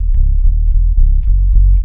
BASS 3 129-L.wav